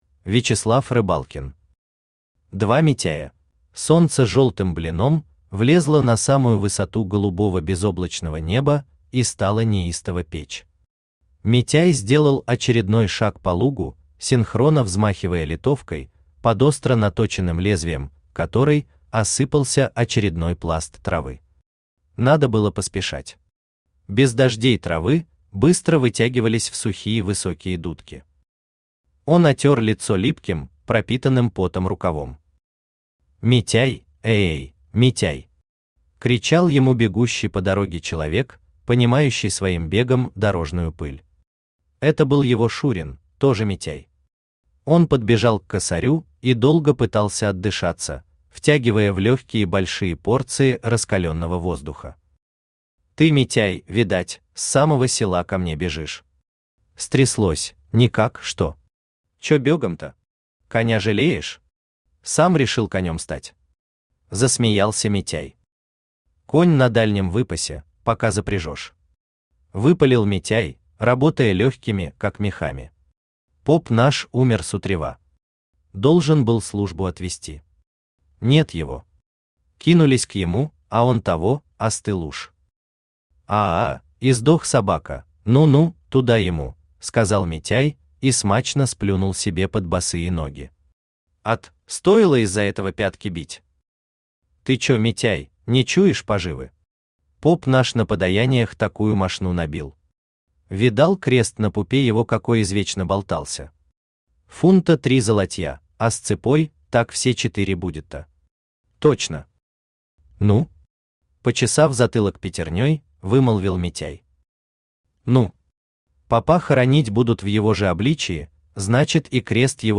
Аудиокнига Два Митяя | Библиотека аудиокниг
Aудиокнига Два Митяя Автор Вячеслав Владимирович Рыбалкин Читает аудиокнигу Авточтец ЛитРес.